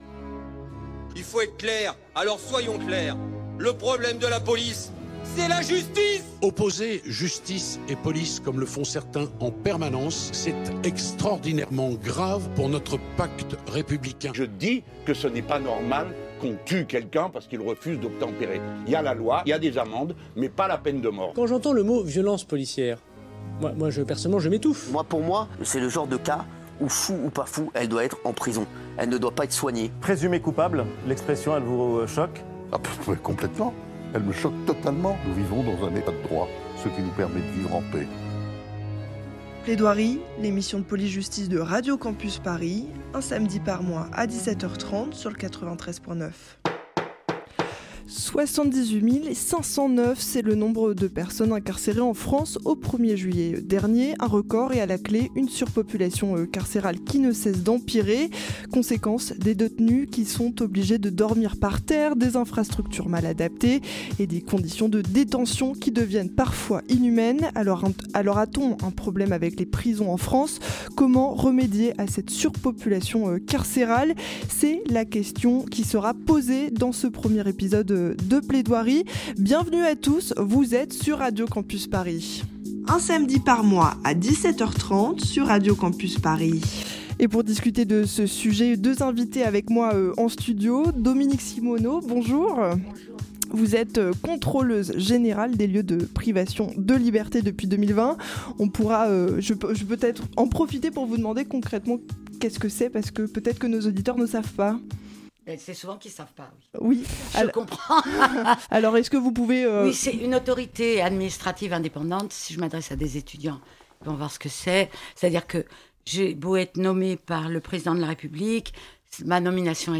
Partager Type Entretien Société samedi 12 octobre 2024 Lire Pause Télécharger 78 509 .
L'émission a été pré-enregistrée dans un studio de Radio Campus Paris.